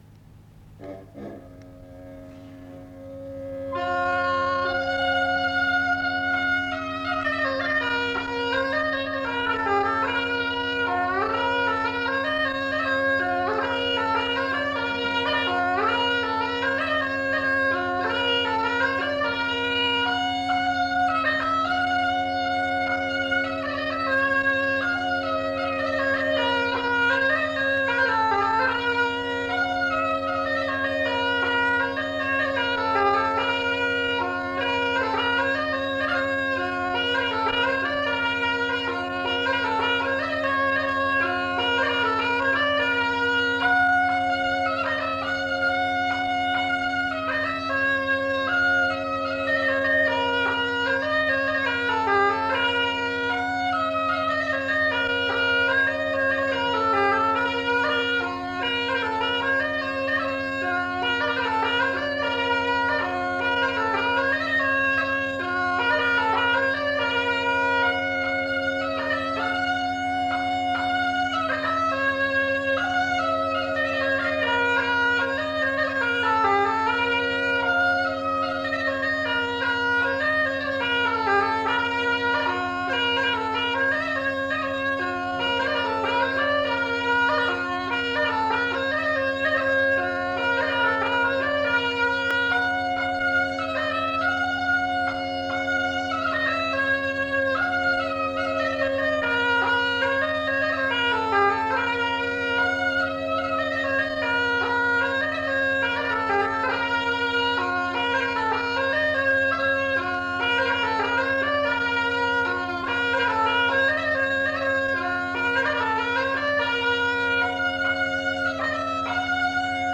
Aire culturelle : Cabardès
Genre : morceau instrumental
Instrument de musique : craba
Danse : mazurka